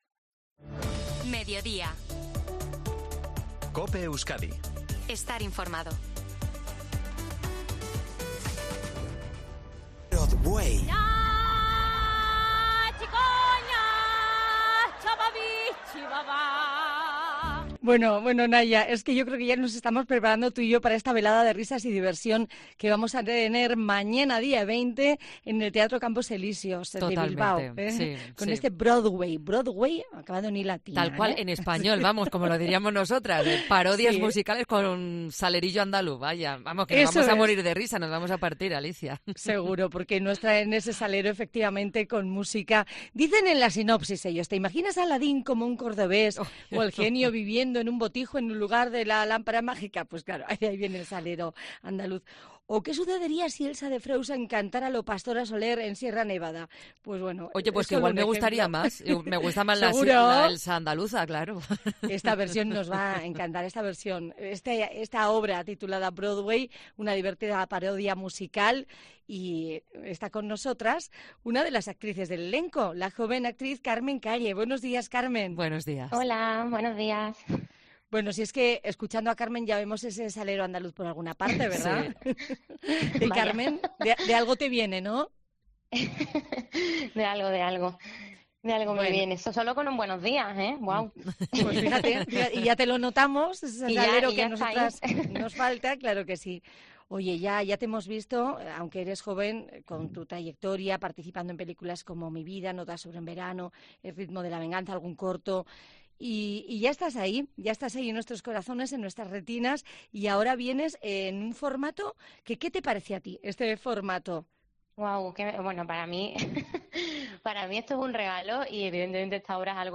actriz